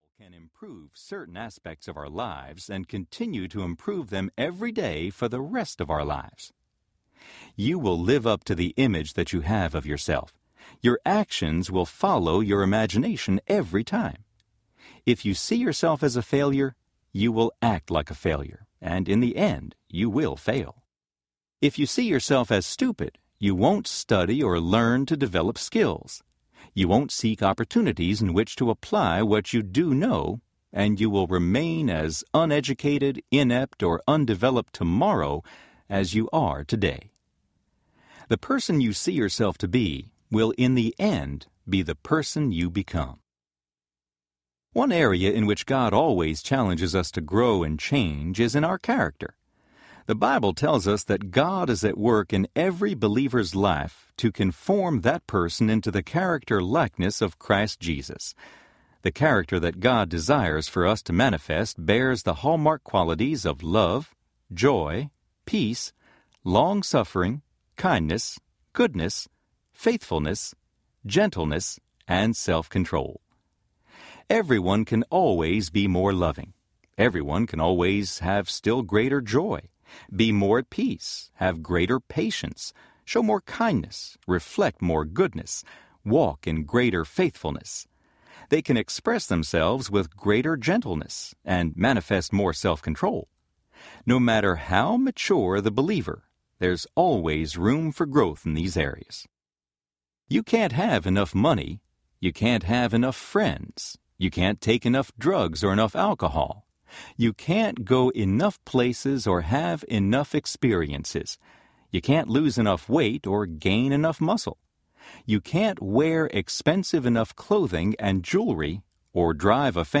Finding Peace Audiobook
Narrator
Charles Stanley
3.45 Hrs. – Unabridged